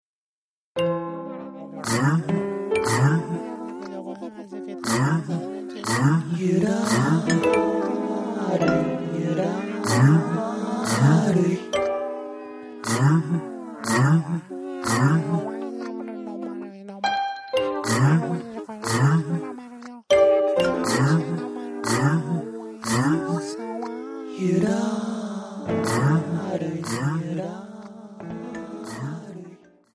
演劇、人形劇、舞踏など主に舞台作品のために作曲された音楽集。